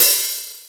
Original creative-commons licensed sounds for DJ's and music producers, recorded with high quality studio microphones.
Crash Cymbal One Shot F Key 01.wav
crash-cymbal-drum-sample-f-key-08-pf7.wav